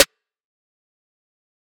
snare4.wav